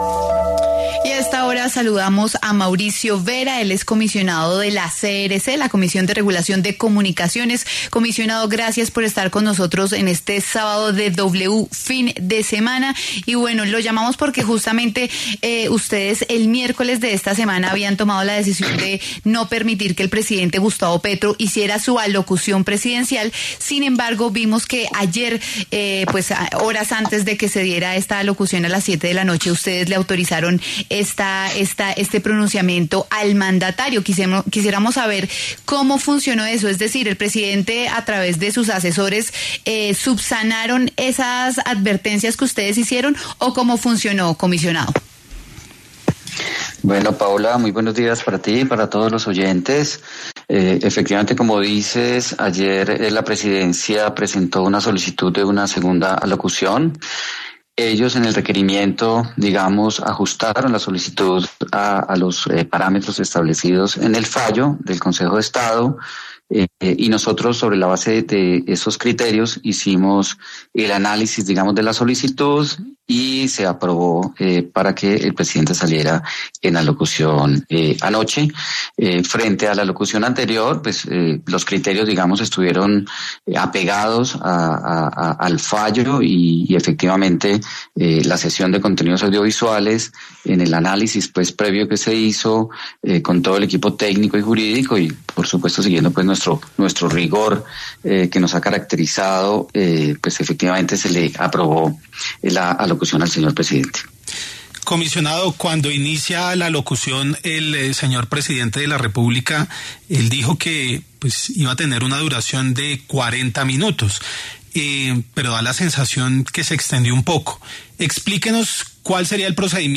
Mauricio Vera Sánchez, comisionado de la CRC, conversó con W Fin de Semana sobre la impugnación al fallo del Consejo de Estado sobre las alocuciones presidenciales.